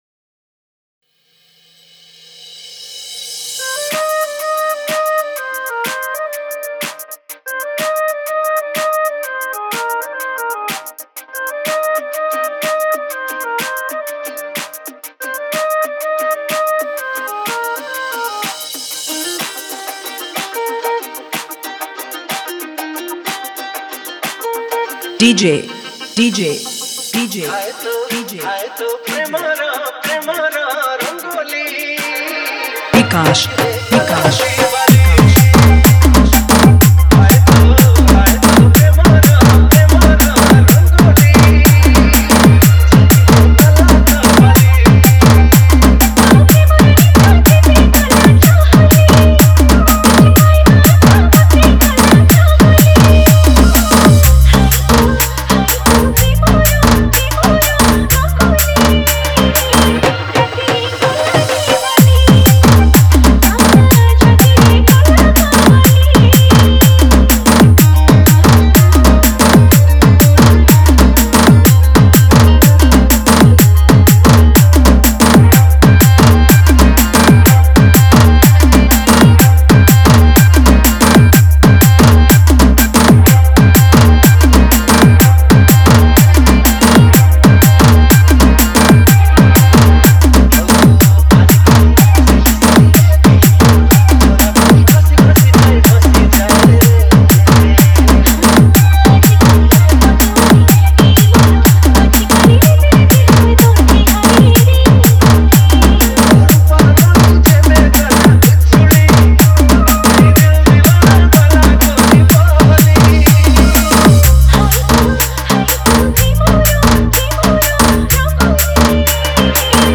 Category:  Odia New Dj Song 2019